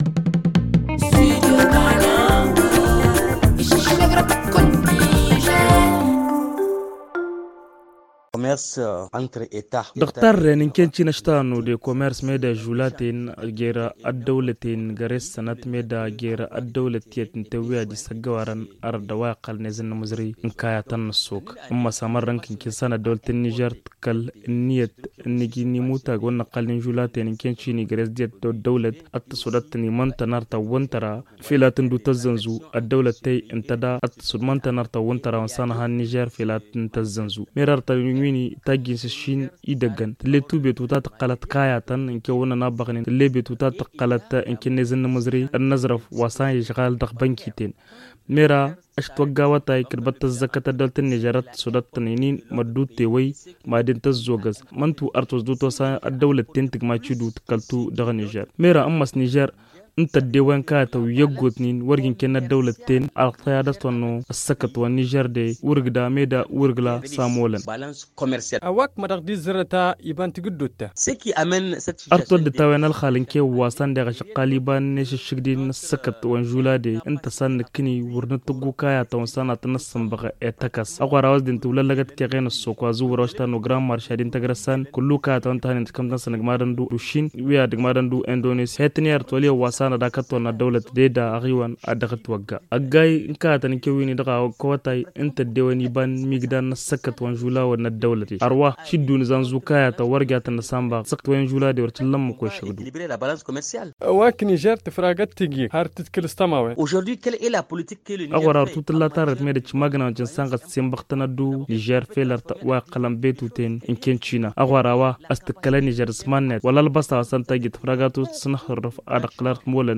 économiste explique au micro